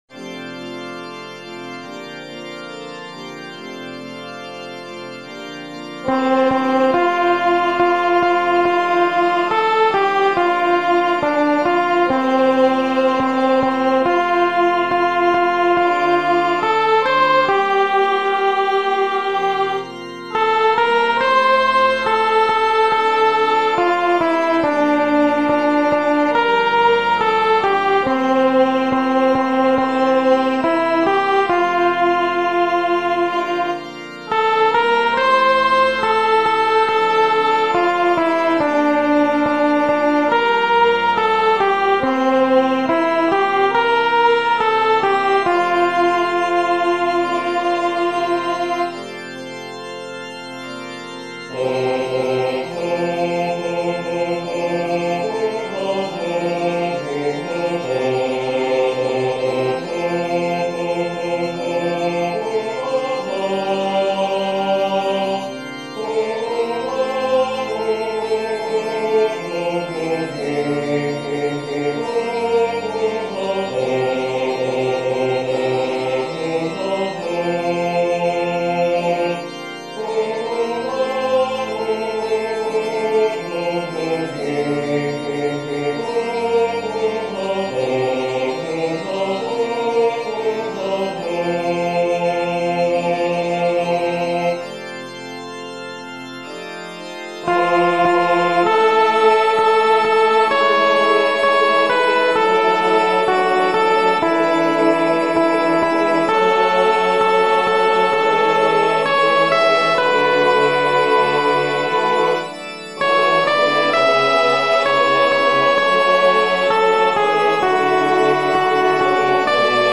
ソプラノ1（フレットレスバス音）